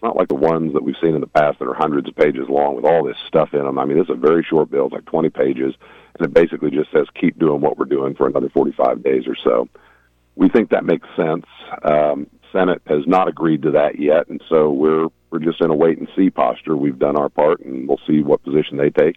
Schmidt spoke about the budget during his monthly update on KVOE’s Morning Show. He says the House has passed a continuing resolution to ensure bills get paid and programs get funded. However, he says this CR is different than ones in the past.